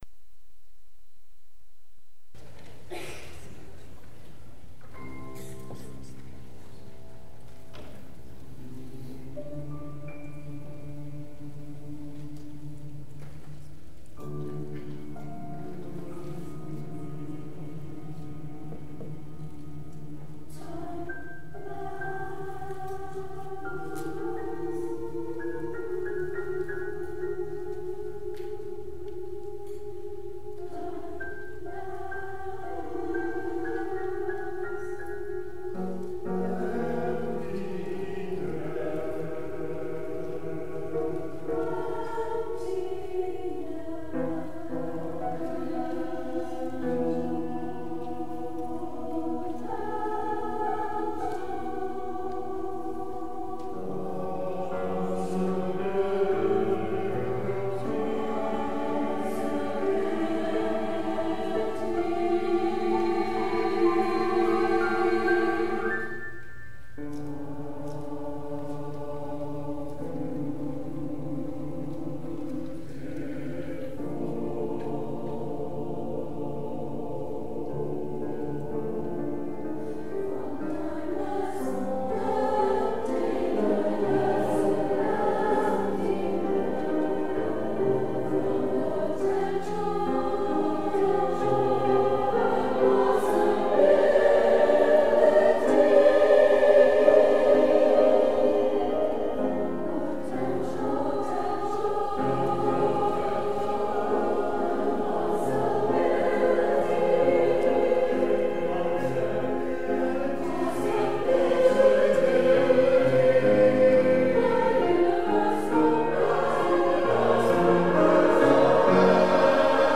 for SATB and Piano